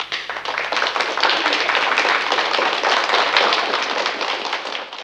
Applause
Crowd Applause 03.m4a